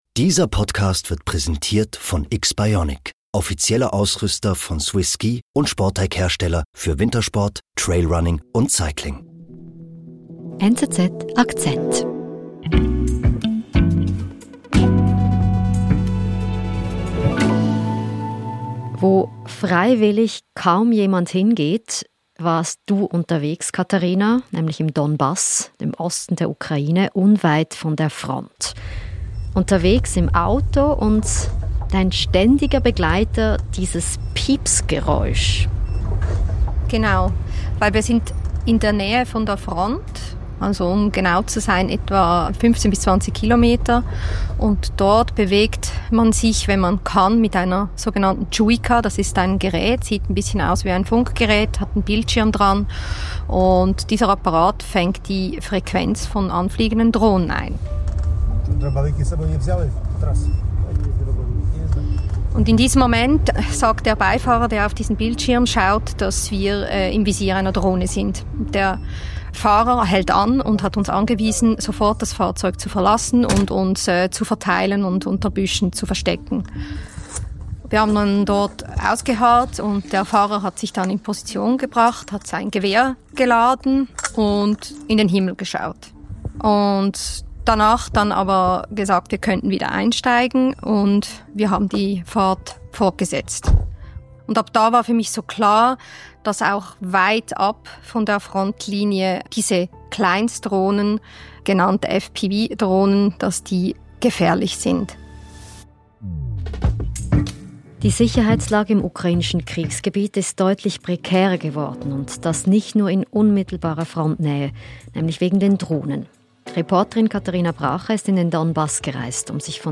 Die russischen Drohnen machen aus der Frontlinie eine kilometerbreite Todeszone. Die Lage im Donbass wird deshalb immer prekärer. Eine Reportage aus dem Frontgebiet.